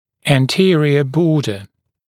[æn’tɪərɪə ‘bɔːdə][эн’тиэриэ ‘бо:дэ]передняя граница